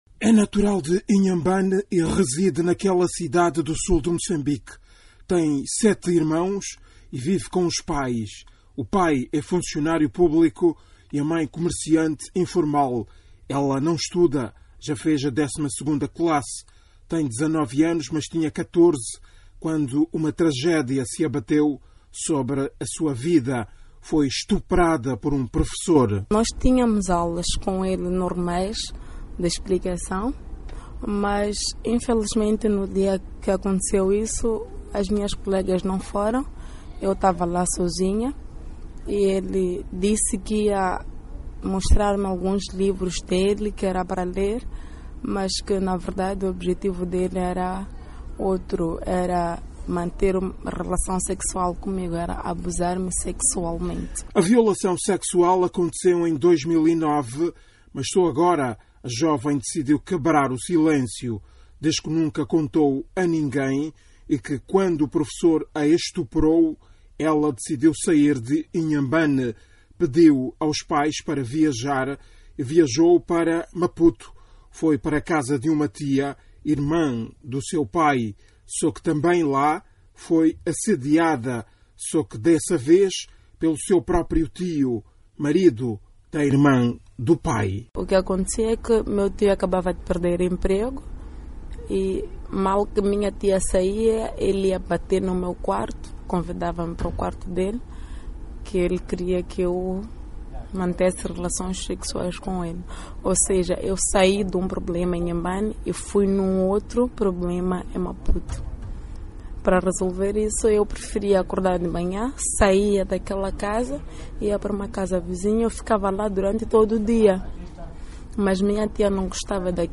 Uma história na primeira pessoa de uma moçambicana
A VOA falou com uma das vítimas.